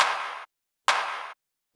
K-5 Clap.wav